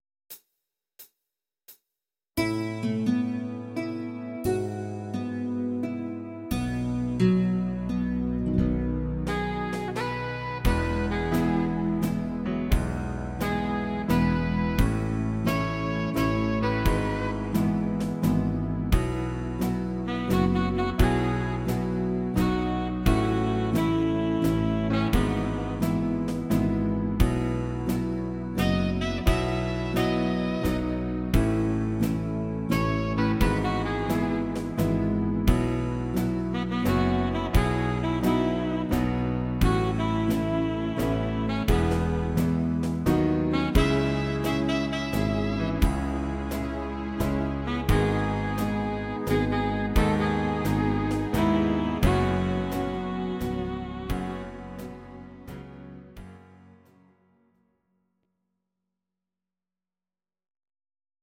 These are MP3 versions of our MIDI file catalogue.
Please note: no vocals and no karaoke included.
Slow waltz version